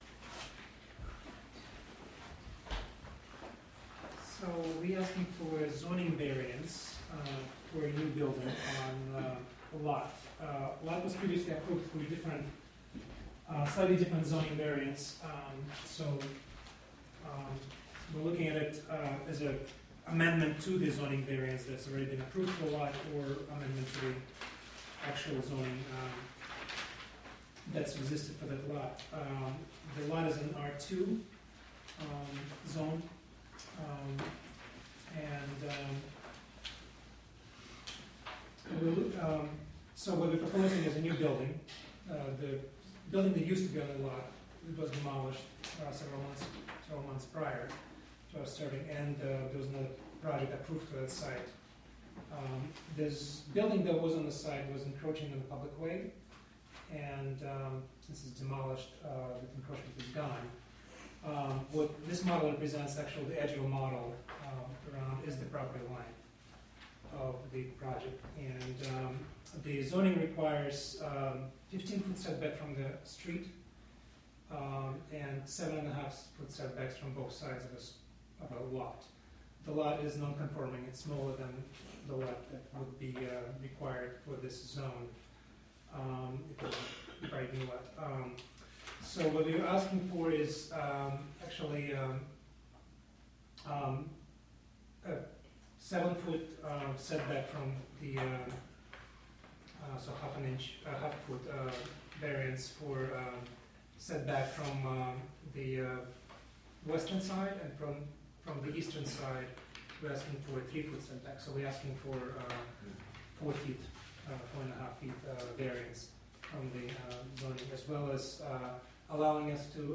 Recorded from a live webstream through a partnership with the City of Hudson and the Wave Farm Radio app and WGXC.